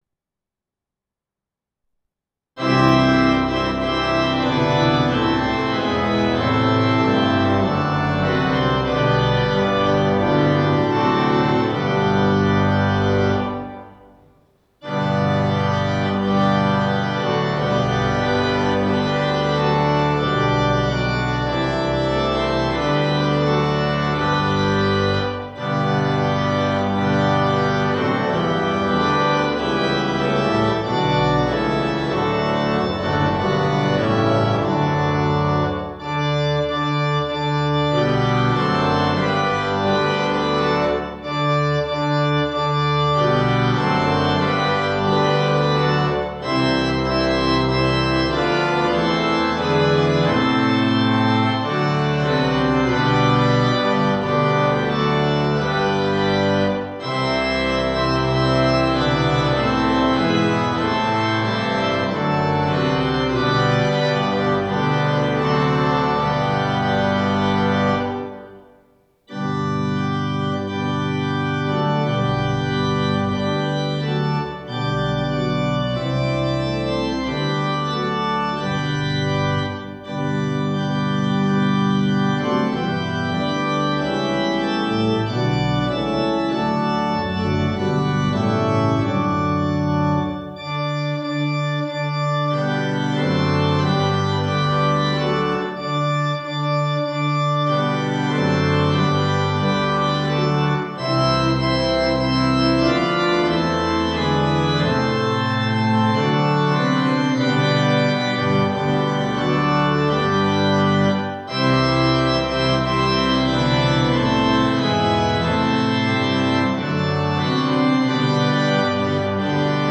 Hark the Herald Angels Sing (Mendels) [Instrumental Version] | Ipswich Hospital Community Choir
Hark-the-Herald-Angels-Sing-Mendels-Instrumental-Version.m4a